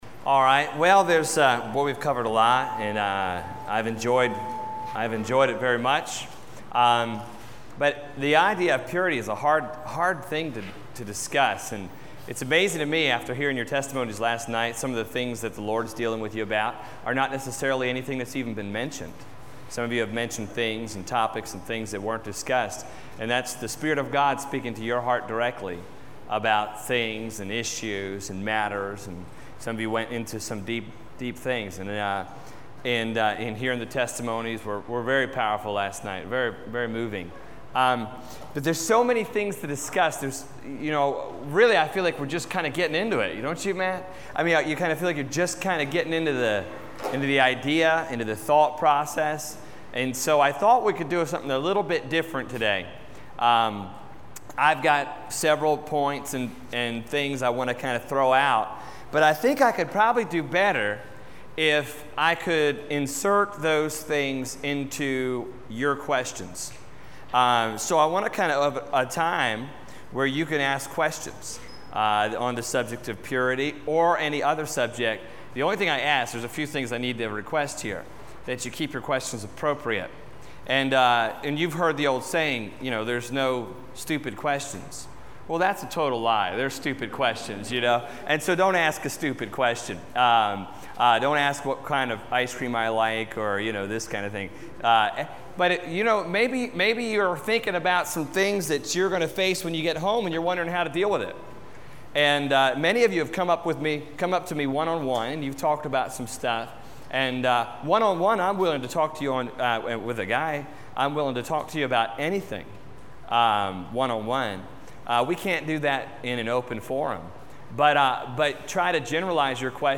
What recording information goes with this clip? Service Type: Teen Camp